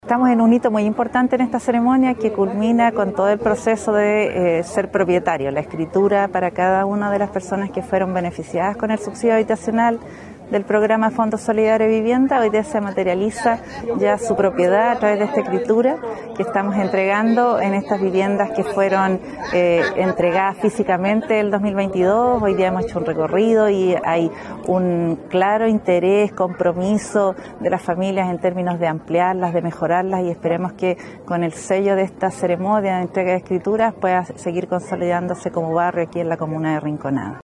La Directora Regional del Serviu, Nerina Paz López, dijo que ahora los vecinos se sienten realmente propietarios.